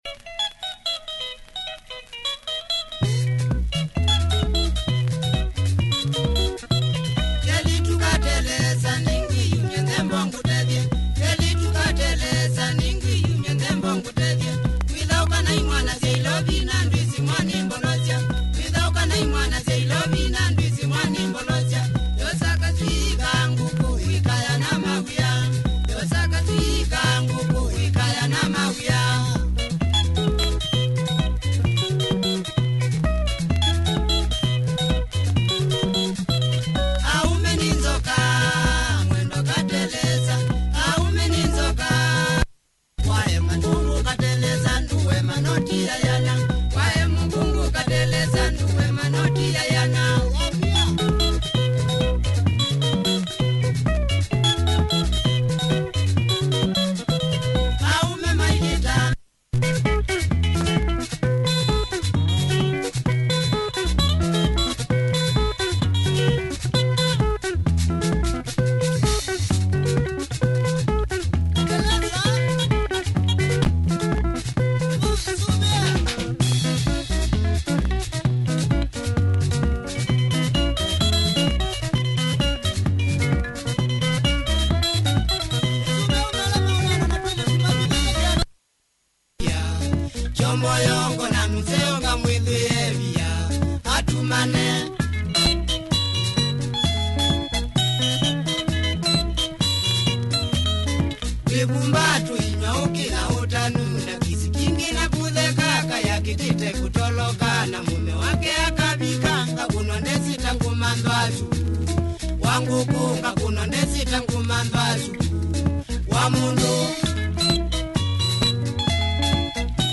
Nice Cavacha benga here with this famous group